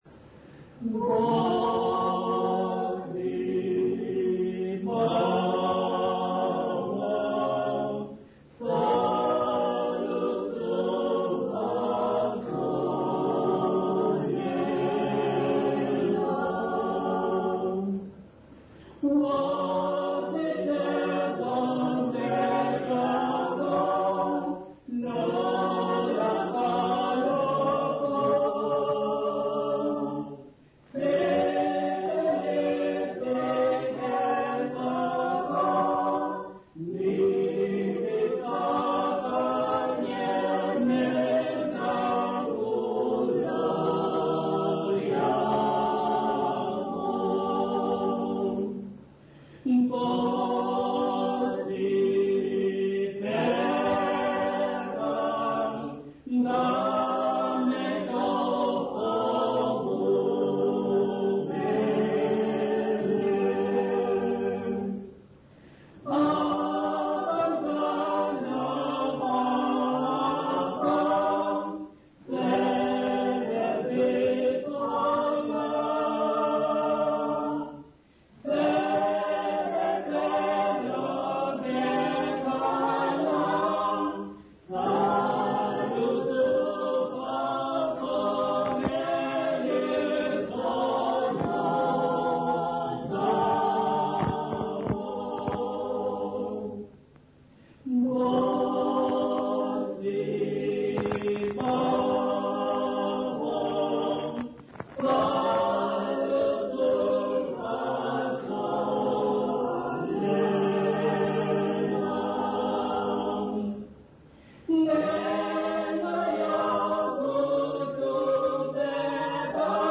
Intshanga church music workshop participants
Sacred music South Africa
Folk music South Africa
Hymns, Zulu South Africa
field recordings
Unaccompanied church hymn.